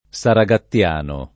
saragattiano
vai all'elenco alfabetico delle voci ingrandisci il carattere 100% rimpicciolisci il carattere stampa invia tramite posta elettronica codividi su Facebook saragattiano [ S ara g att L# no ] o saragatiano [ S ara g at L# no ] agg.